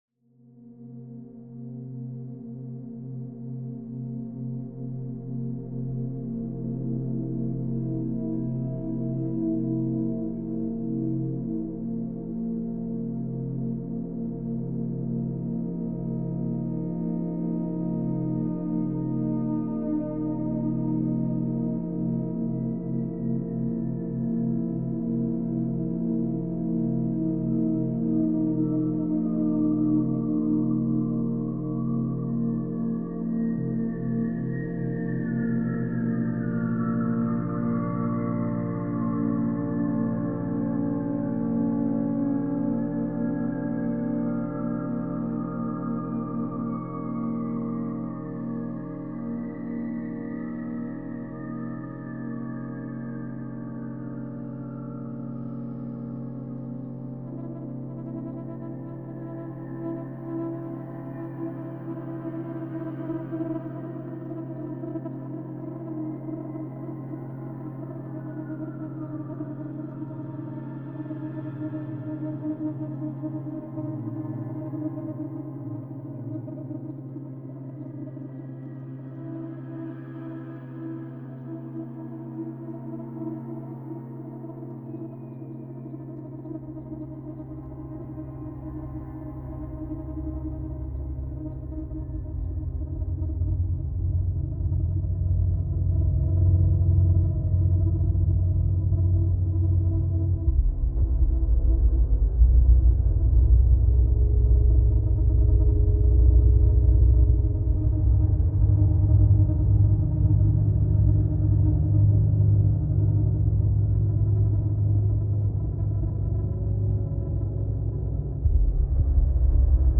Theremin – ein elektronisches Instrument, das berührungslos gespielt wird und schwebende, sinusförmige Klänge erzeugt: von zarten Vibratos bis zu aufheulenden Glissandi. In Kombination mit akustischen Instrumenten entstehen Klänge, die Circes innere Zerrissenheit zwischen Göttlichkeit und Menschlichkeit spiegeln und von der Entdeckung ihrer Kraft berichten.